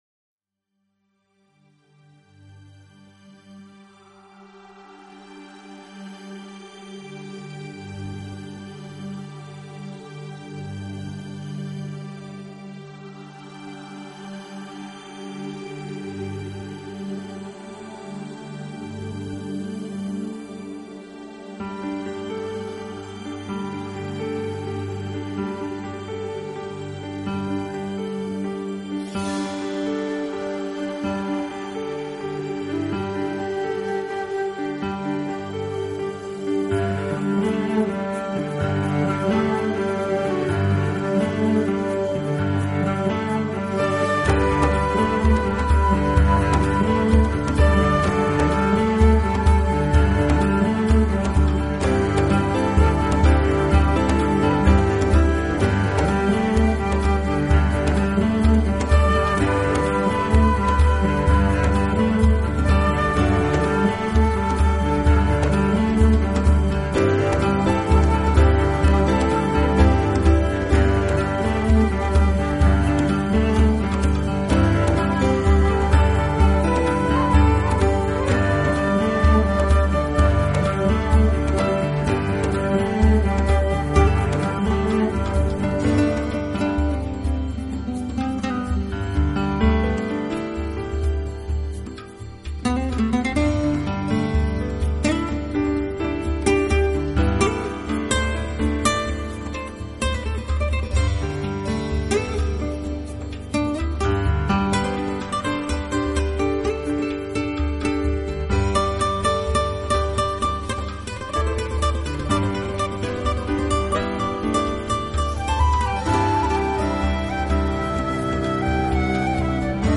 【新世纪纯音乐】
这种音乐是私密的，轻柔的，充满庄严感并总